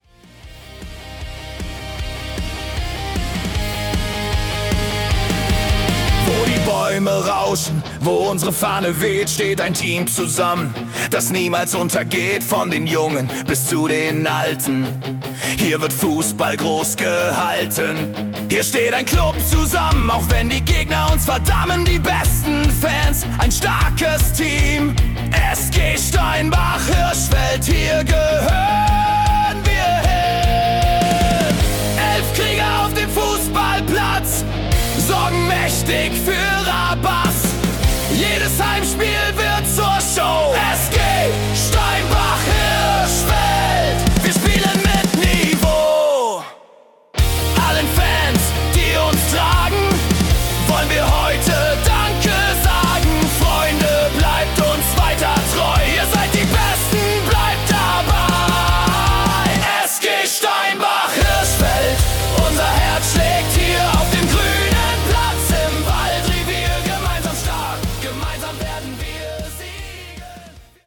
Vereinshymne